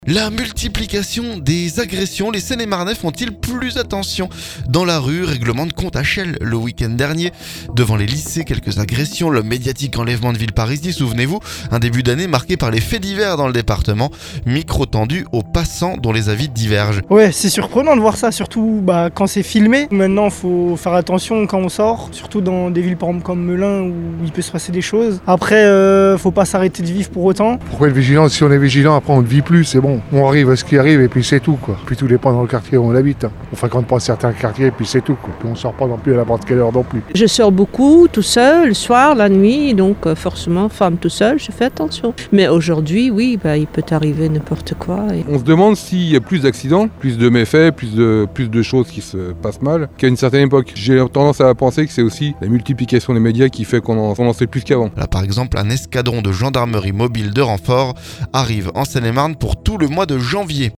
Micro tendu aux passants dont les avis divergent.